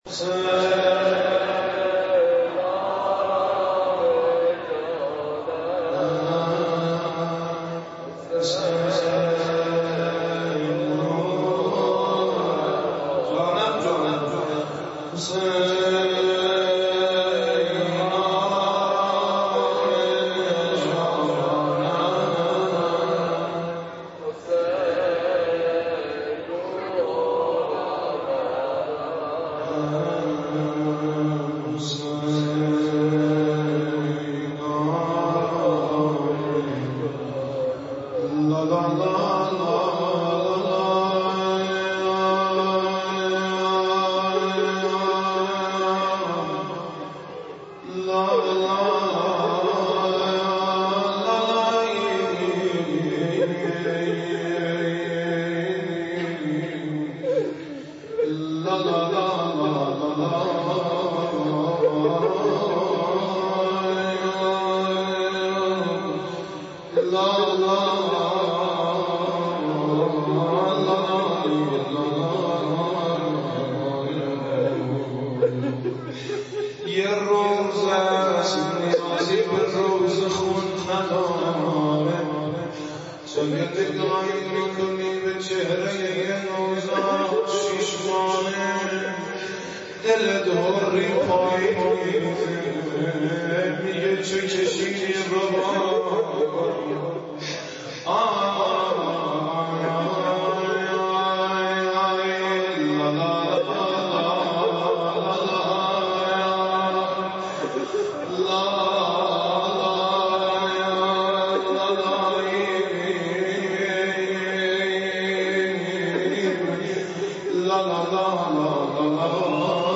.مراسم ویژه شب هفتم ماه محرم در سقاخانه حضرت اباالفضل (ع) گلپایگان با حضور عاشقان حضرت اباعبدلله الحسین (ع) برگزار گردید.
روضه خوانی